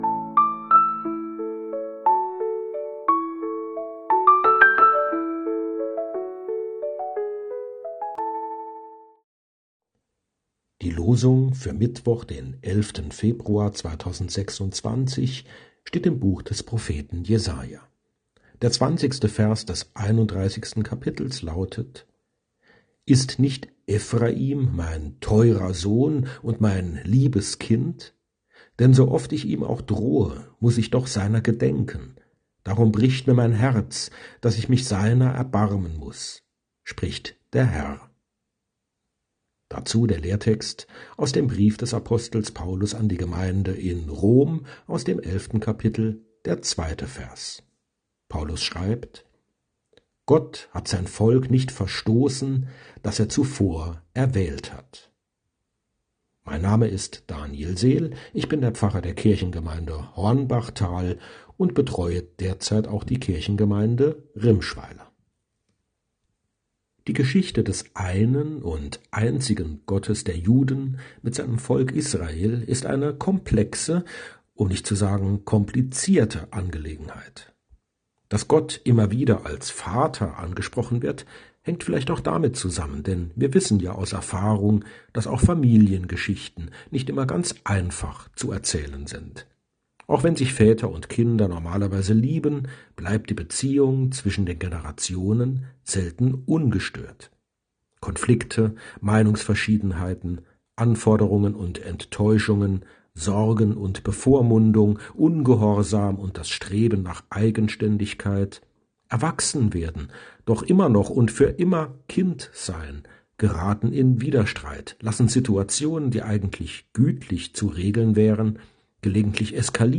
Losungsandacht für Mittwoch, 11.02.2026